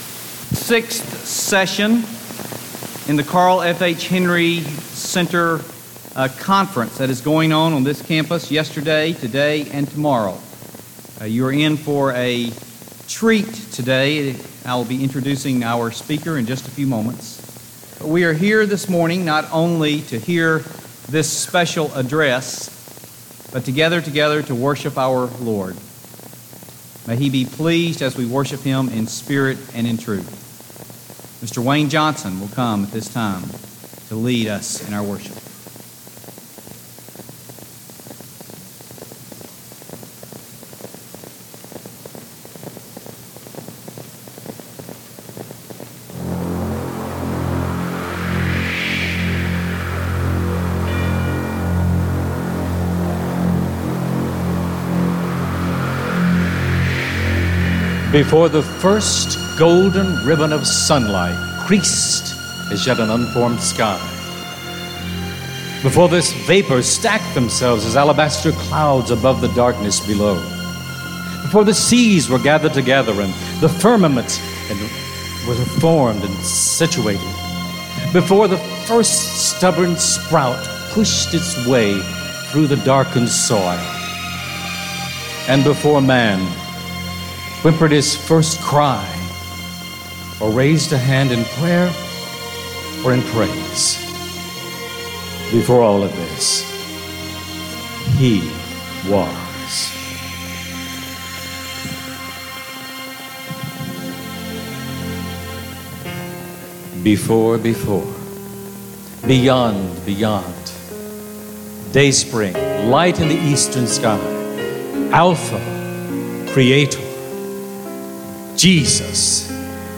Chapel: Al Mohler
Carl F.H. Henry Conference, Sixth Session